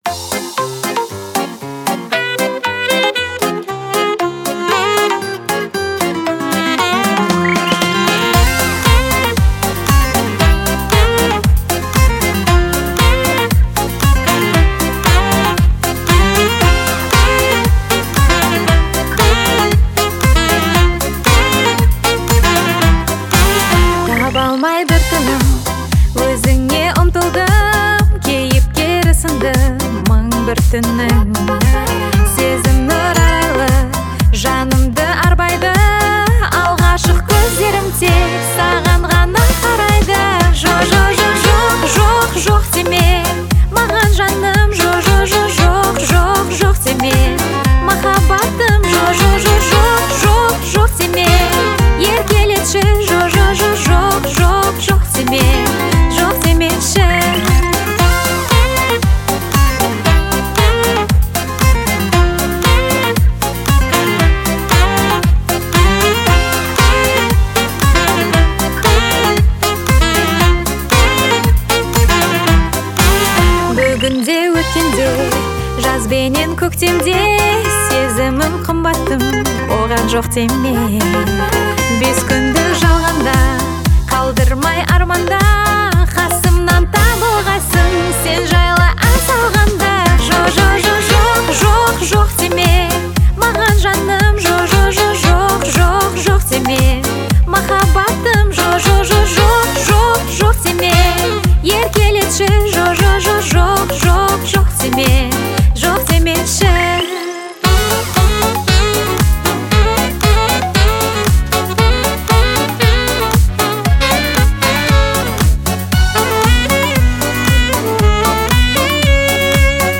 это эмоциональная песня в жанре поп